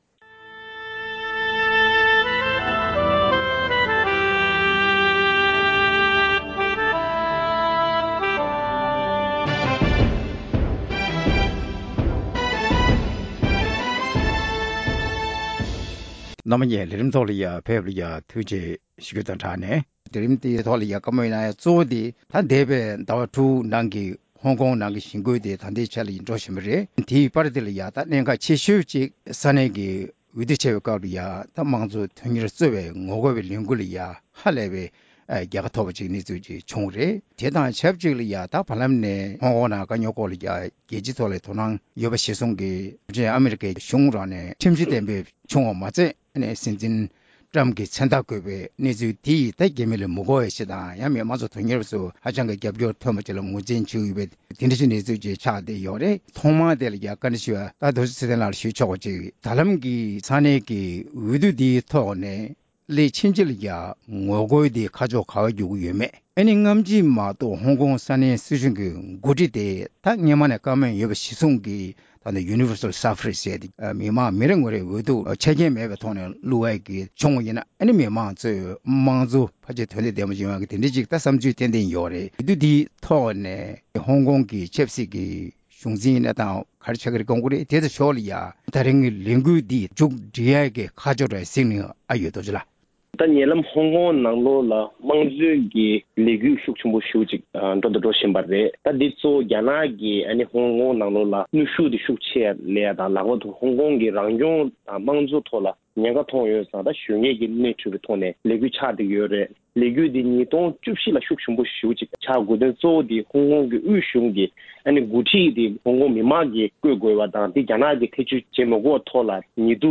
རྒྱ་ནག་གིས་ཧོང་ཀོང་ནང་ཤར་ཏུརྐིསེ་ཐན་ནང་བཞིན་དྲག་གནོན་བྱེད་རྩིས་ཡོད་པ་དེར་ཨོ་སེ་ཊེ་ལི་ཡས་སྐྱོན་བརྗོད་བྱེད་བཞིན་པ་སོགས་ཀྱི་ཐད་གླེང་མོལ།